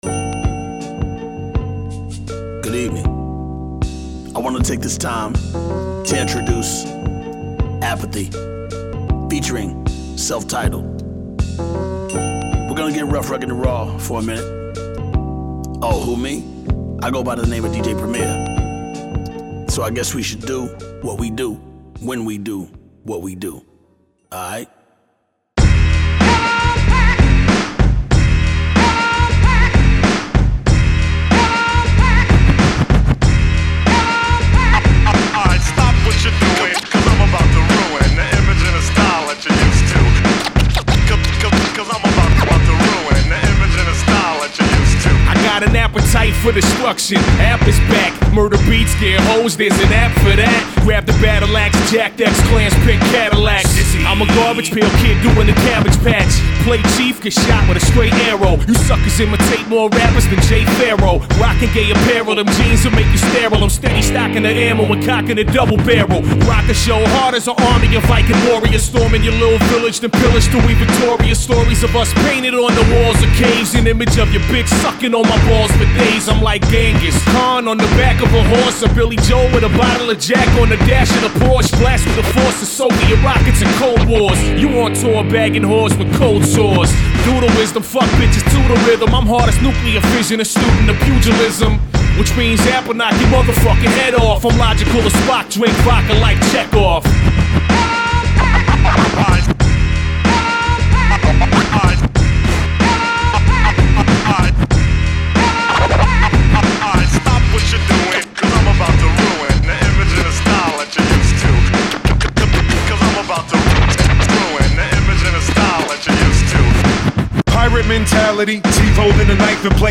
hardcore, classic hip-hop album